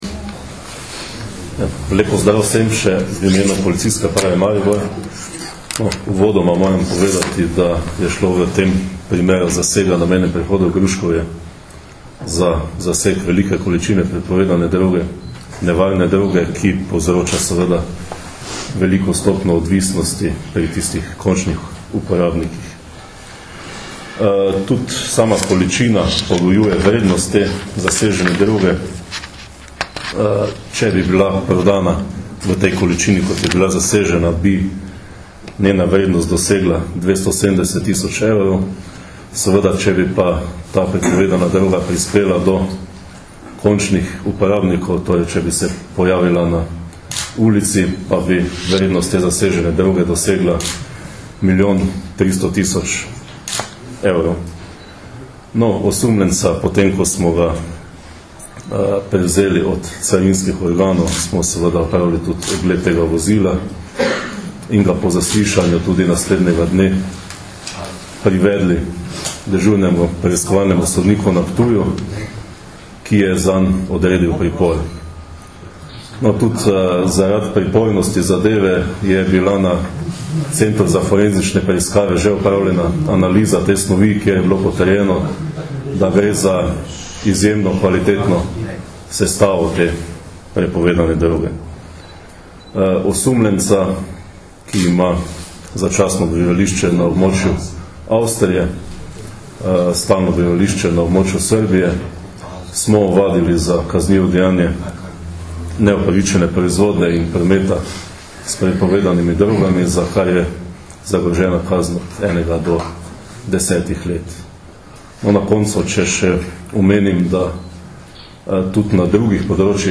Predstavniki slovenske policije in carine so na novinarski konferenci v Mariboru danes, 3. novembra 2009, podrobneje predstavili enega večjih zasegov prepovedane droge v zadnjem času.
Zvočni posnetek izjave